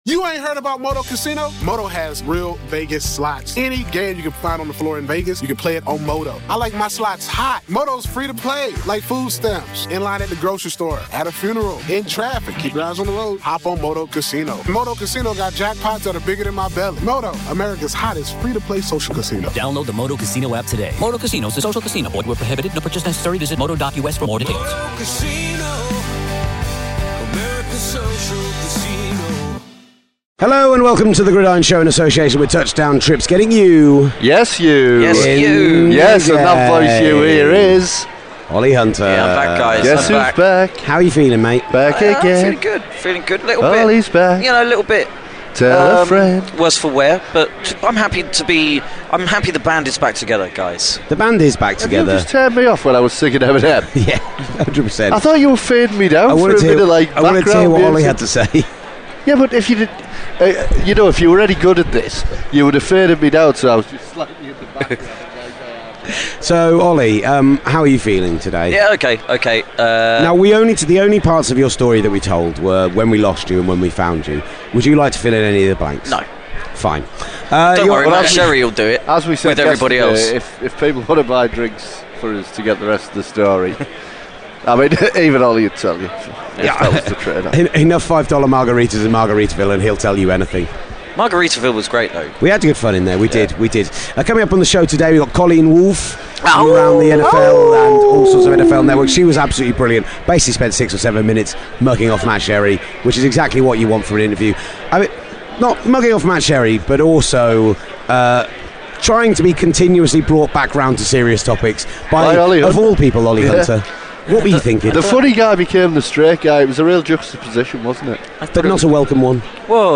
They chat with NFL Network's Colleen Wolfe, four-time Super Bowl champion Rocky Bleier, Hall-of-Famer Mike Haynes and Vikings GM Rick Spielman. The lads round off the show by interviewing Steve Spagnuolo, who was the DC for the Giants' Super Bowl upset of the nearly-perfect 2007 Patriots.